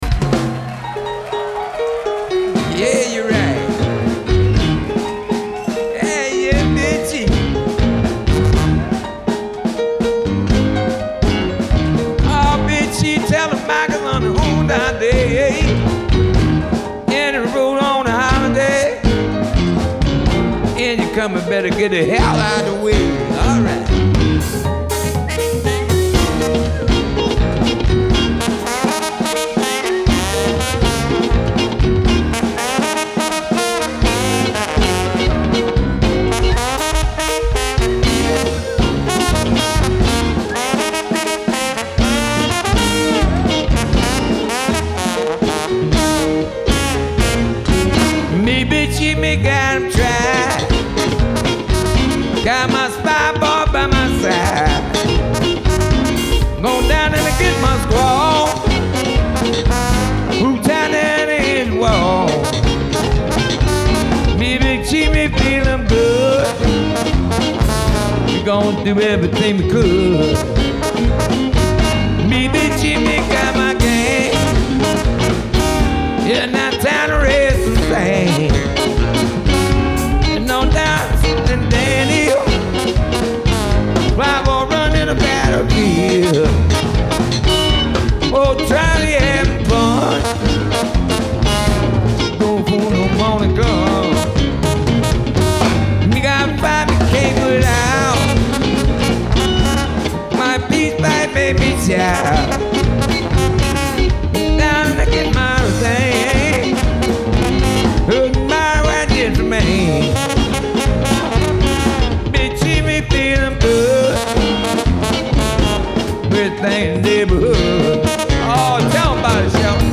Genre : Jazz